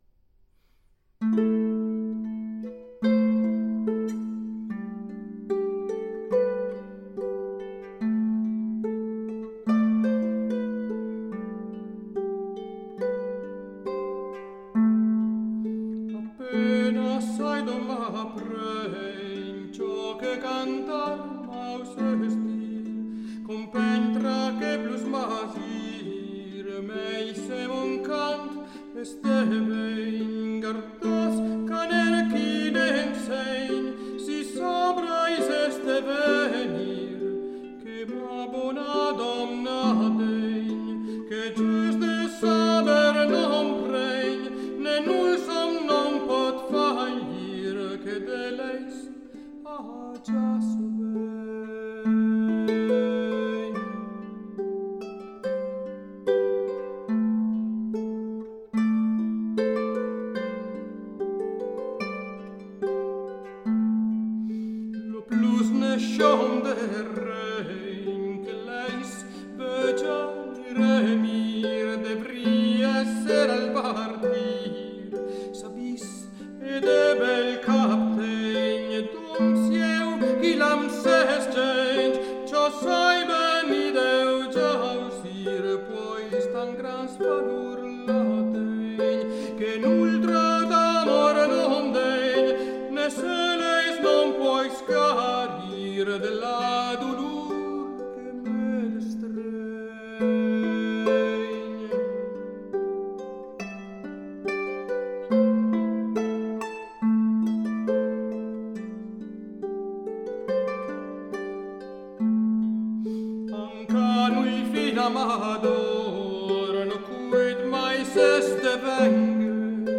Anteprima delle anteprime, un paio di minuti di Apenas sai don m’apreing, di Raimon de Miraval (non tagliata, non mixata, direttamente dal luogo di registrazione).